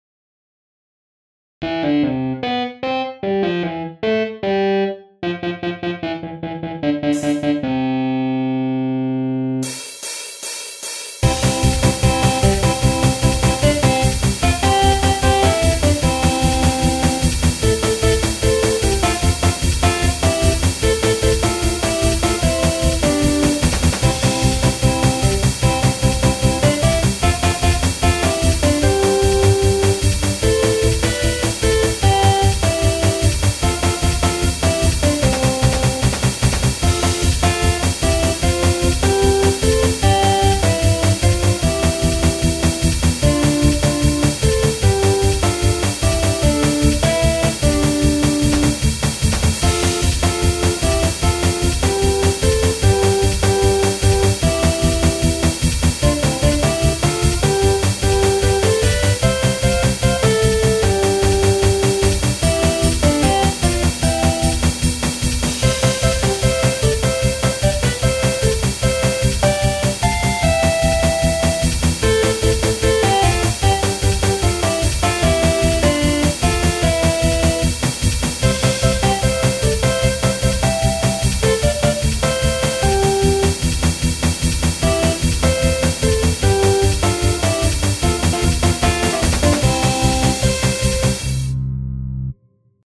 アップテンポ明るい激しい